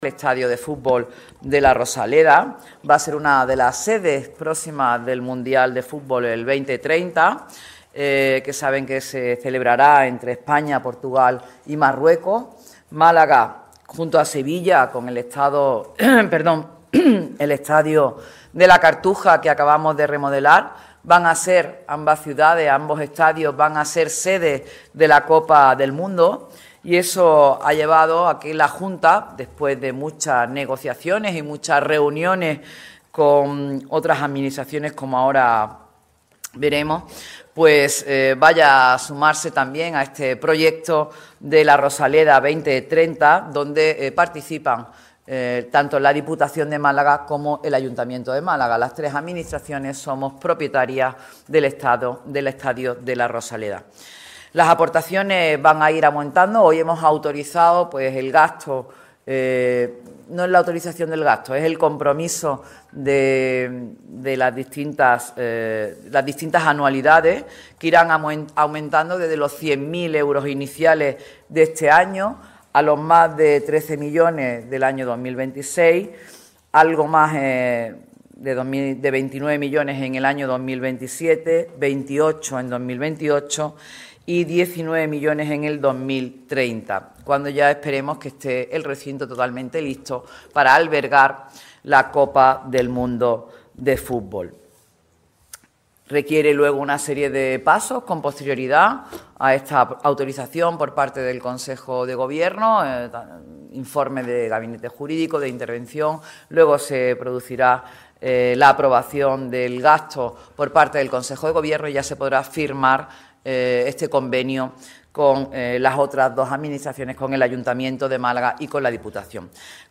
La consejera de Economía, Hacienda y Fondos Europeos de la Junta de Andalucía y portavoz de gobierno andaluz, Carolina España, ha comparecido en rueda de prensa de consejo de Gobierno ante los medios de comunicación para hablar sobre dos de los proyectos que tiene la ciudad entre manos como el Hospital y la remodelación del estadio de La Rosaleda.